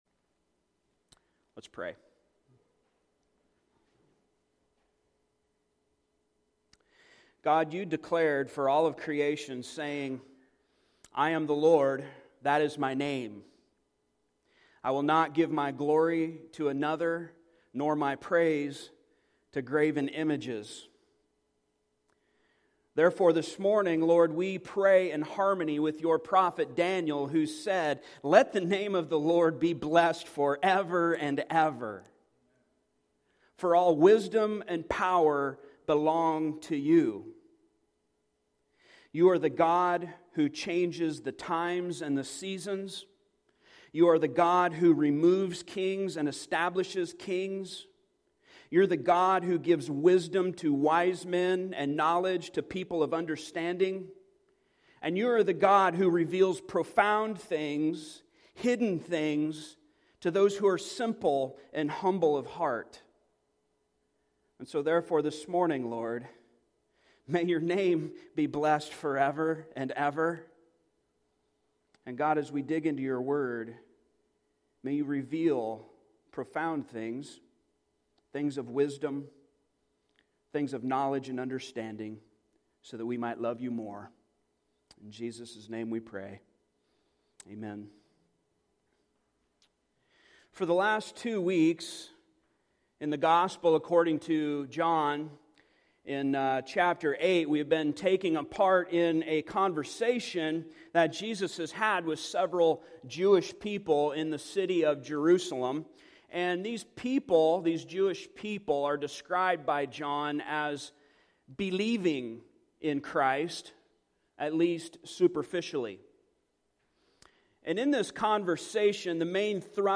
Passage: John 8:48-59 Service Type: Sunday Morning